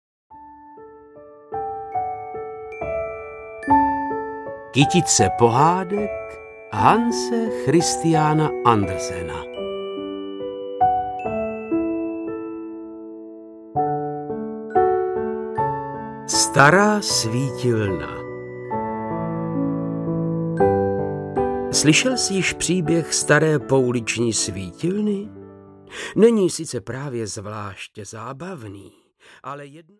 Ukázka z knihy
• InterpretVáclav Knop
stara-svitilna-audiokniha